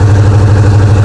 Engine
E36_Low.wav